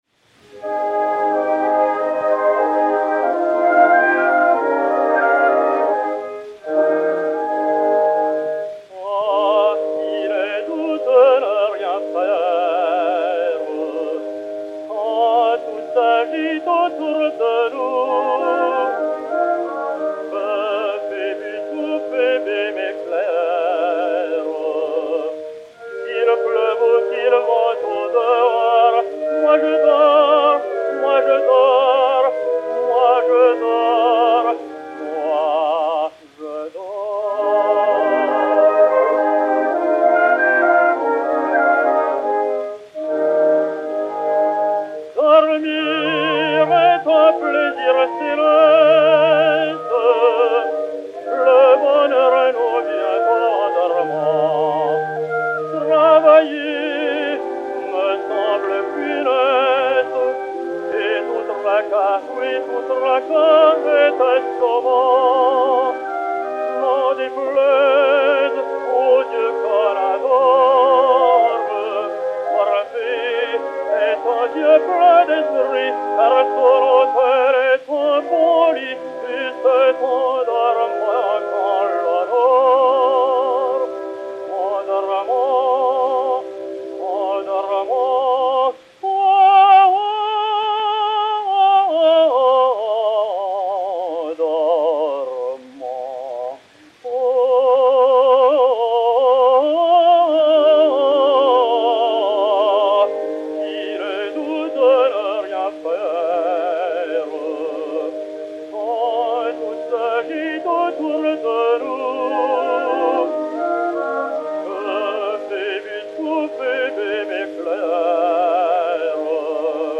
et Orchestre
Disque Pour Gramophone 4-32224, mat. 16197u, enr. le 27 décembre 1910